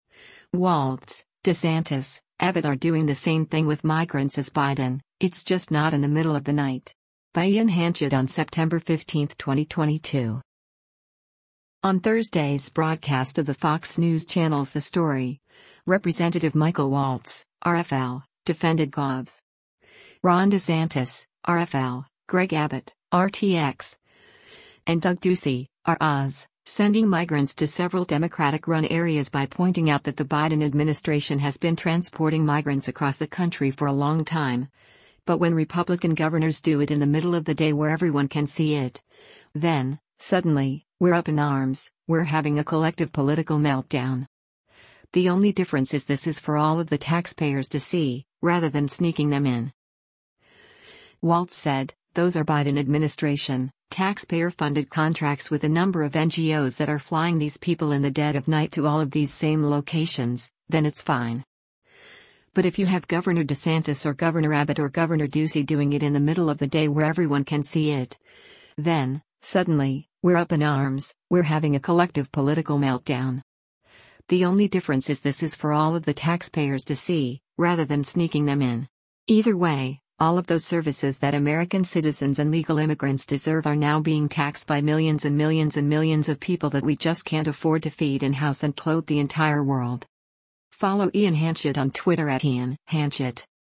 On Thursday’s broadcast of the Fox News Channel’s “The Story,” Rep. Michael Waltz (R-FL) defended Govs. Ron DeSantis (R-FL), Greg Abbott (R-TX), and Doug Ducey (R-AZ) sending migrants to several Democratic-run areas by pointing out that the Biden administration has been transporting migrants across the country for a long time, but when Republican governors do it “in the middle of the day where everyone can see it, then, suddenly, we’re up in arms, we’re having a collective political meltdown.